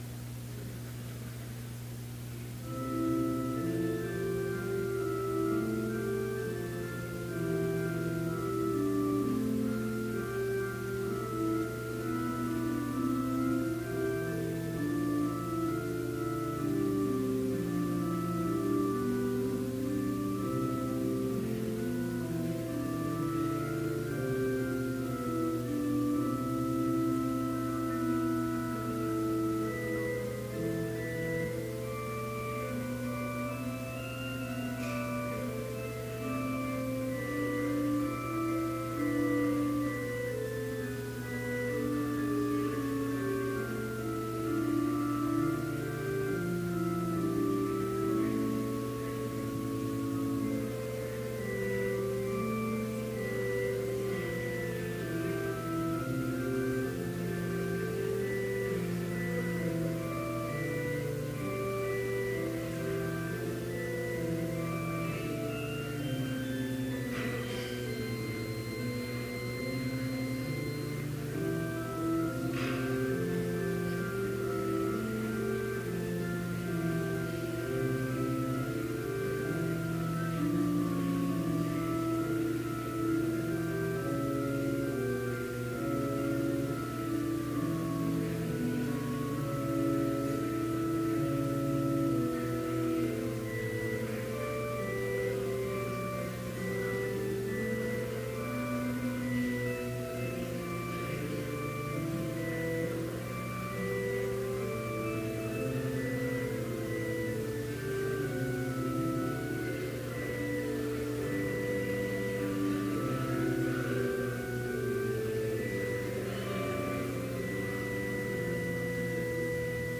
Complete service audio for Chapel - November 6, 2018